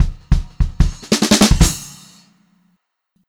152H2FILL1-L.wav